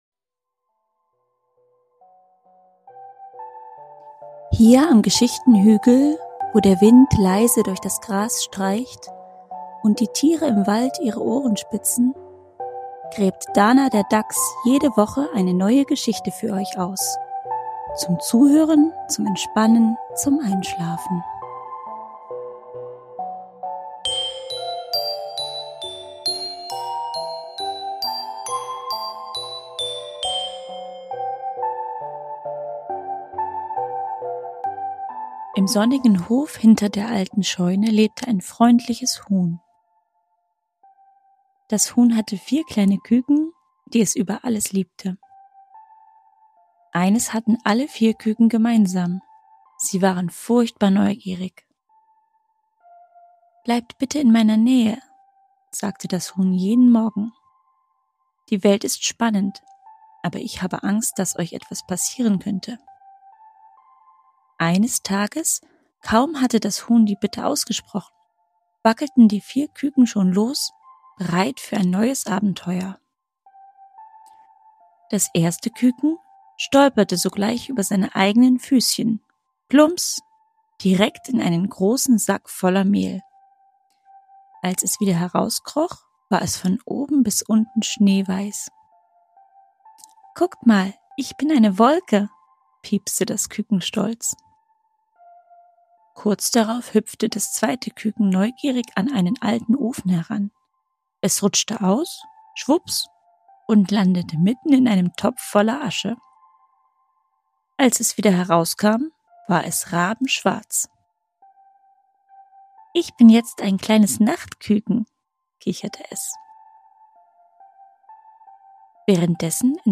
Ruhige Geschichten für Kinder – zum Entspannen, Zuhören und Einschlafen.